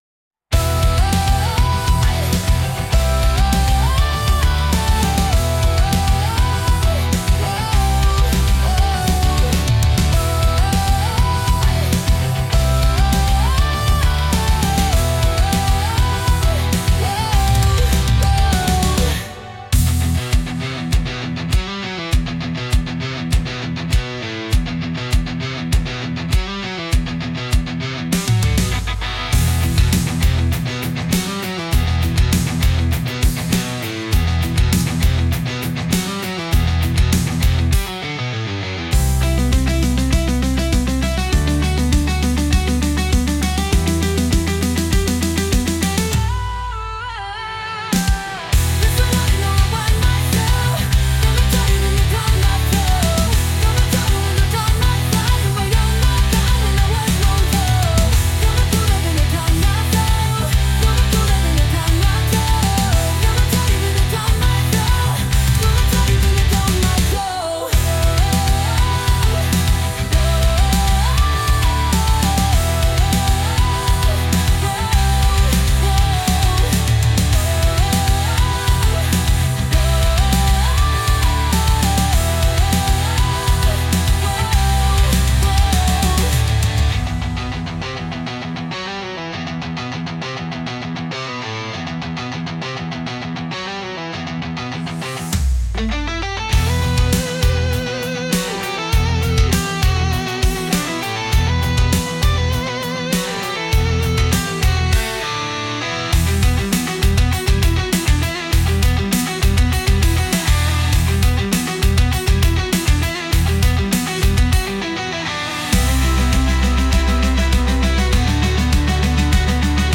Lyrics: instrumental